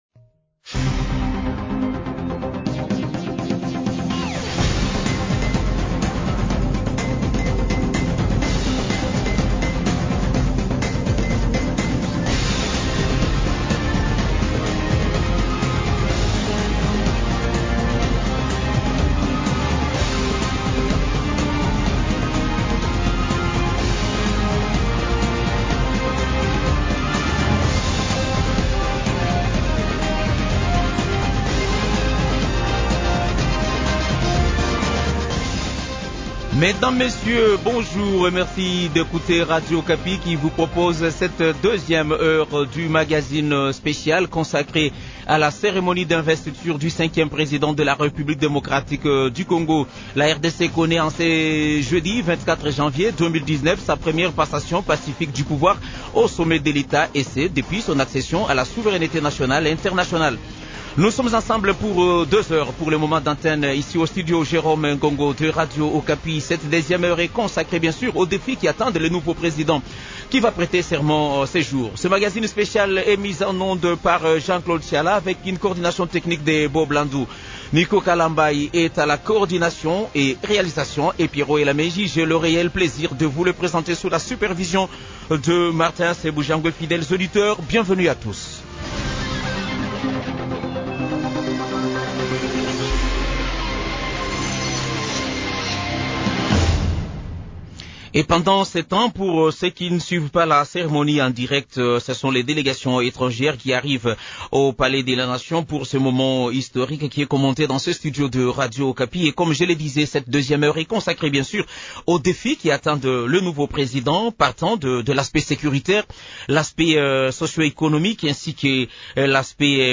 Radio Okapi reçoit dans ses studios de Kinshasa plusieurs invités pour commenter l’investiture du président Félix Tshisekedi comme président de la République.
Trois analystes débattent de ce thème